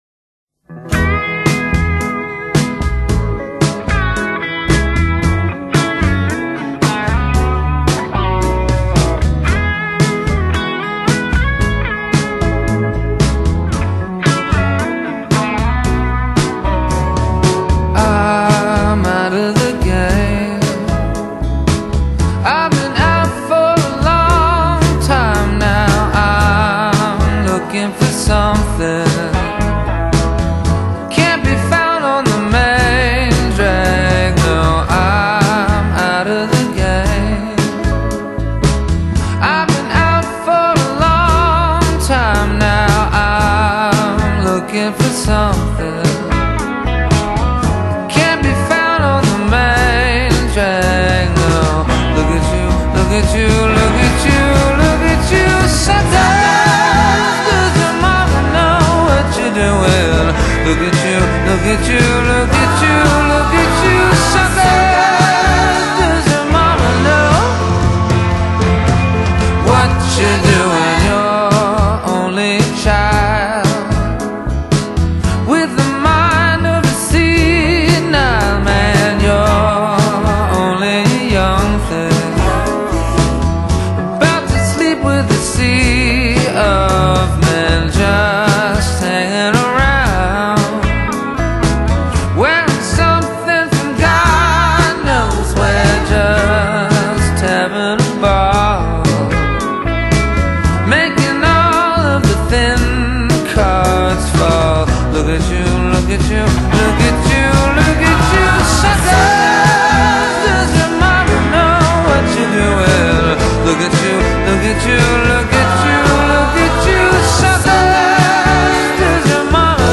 Indie Pop | mp3 CBR 320 kbps | 50:21 min | 115 MB